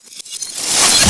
casting_charge_matter_grow_02.wav